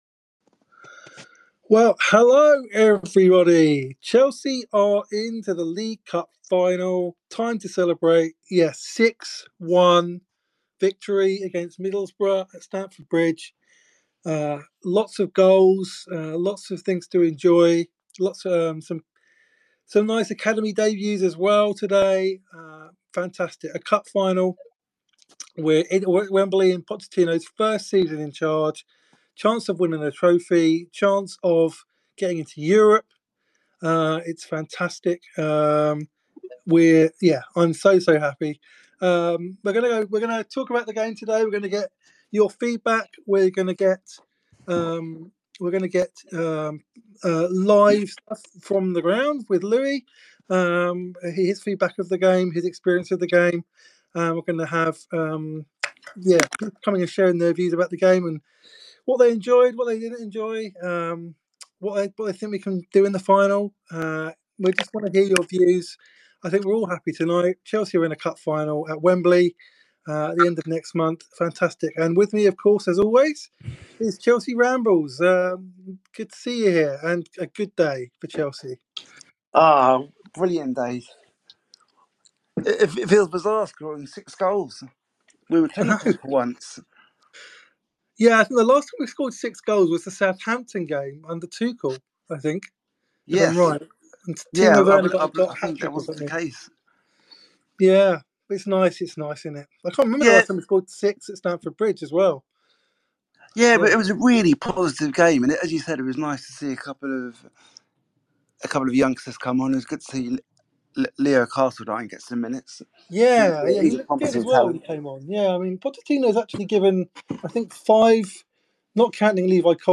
take your calls after Chelsea's dominant display against a lacklustre Middlesbrough.